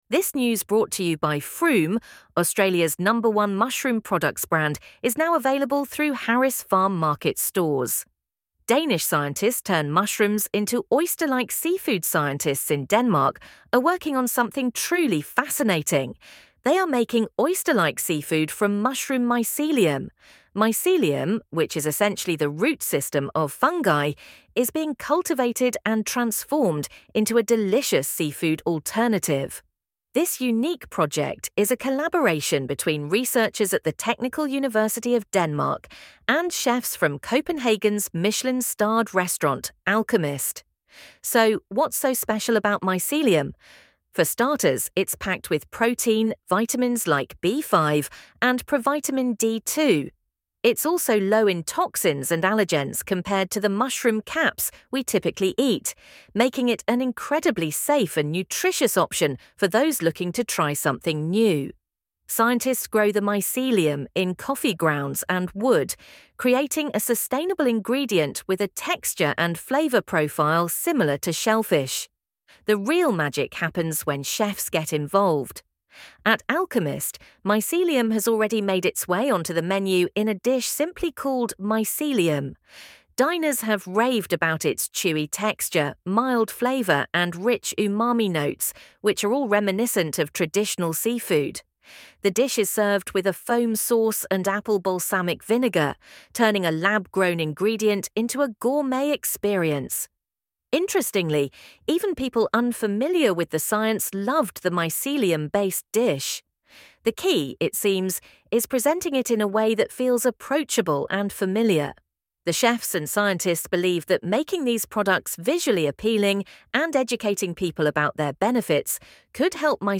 LISTEN TO THIS AUDIO ARTICLE Scientists in Denmark are working on something truly fascinating - they are making oyster-like seafood from mushroom mycelium.